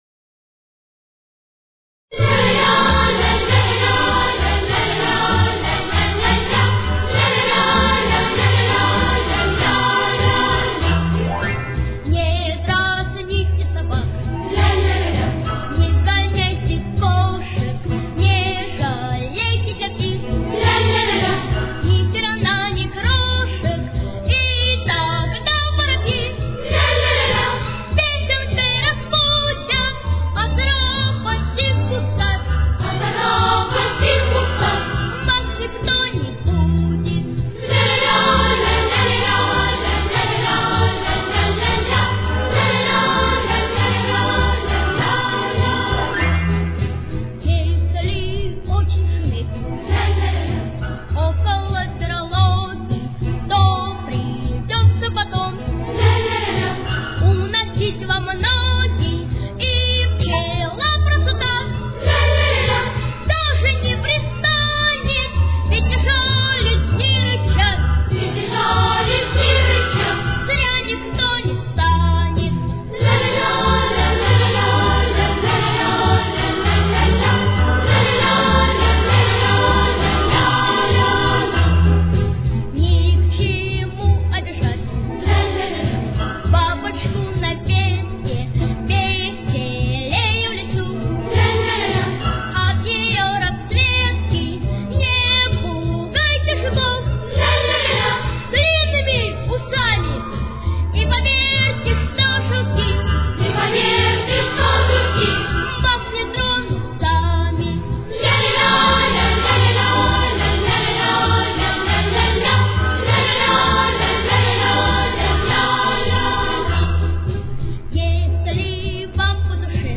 Ми минор. Темп: 202.